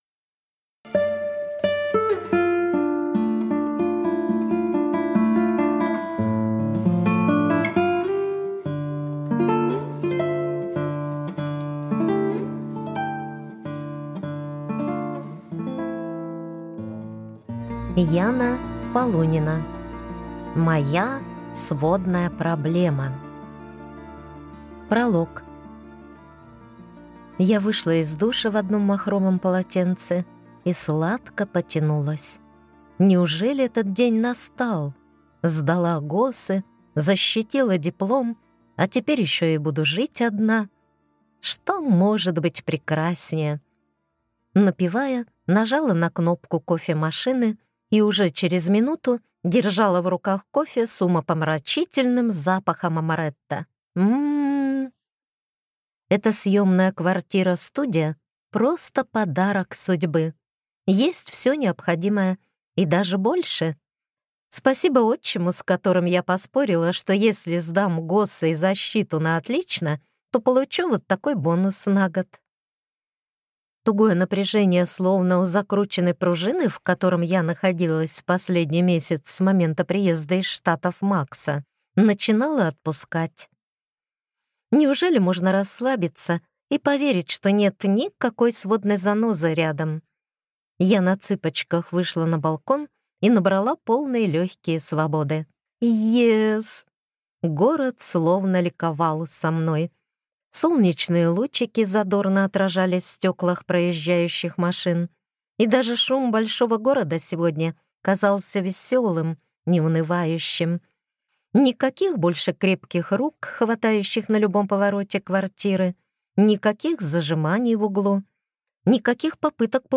Аудиокнига Моя сводная проблема | Библиотека аудиокниг